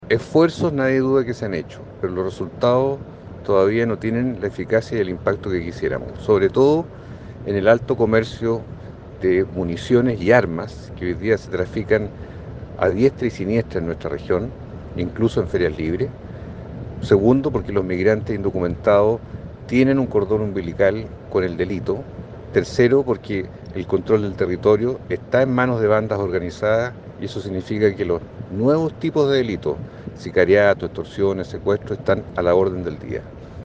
En el marco de la sesión especial de la Comisión de Seguridad Ciudadana de la Cámara de Diputados realizada en Rancagua, el senador por la región de O’Higgins, Juan Luis Castro, destacó la importancia de esta instancia para sincerar la situación actual en materia de seguridad pública.